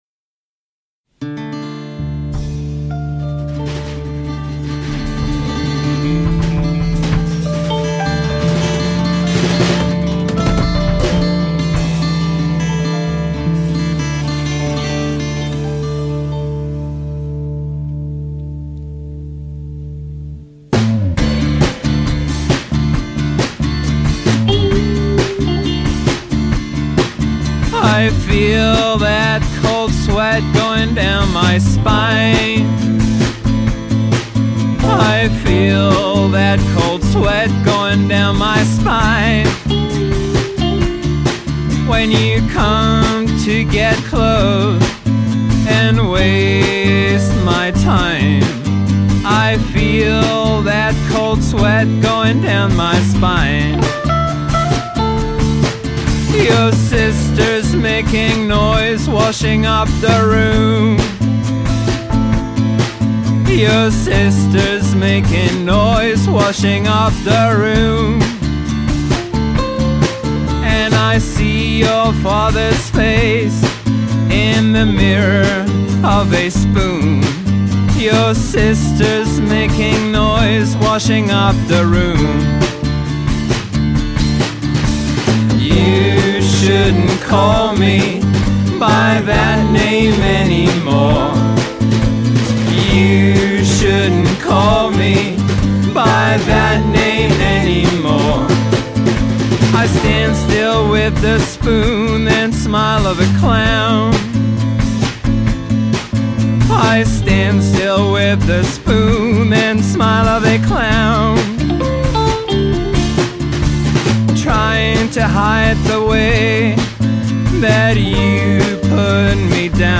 an die dire straits erinnernden eröffnungssong